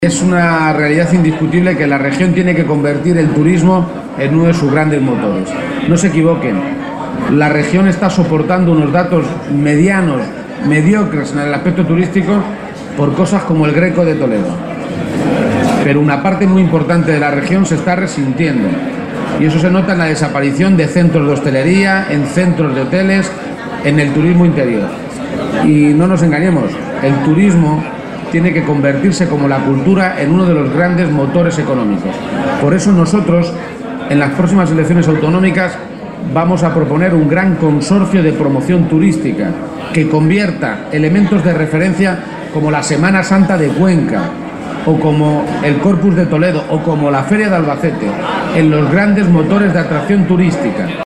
Page se pronunciaba de esta manera en su comparecencia ante los medios de comunicación, en Albacete, pocos minutos antes del acto de entrega de los Premios Pablo Iglesias que otorga la agrupación local socialista de la capital albaceteña.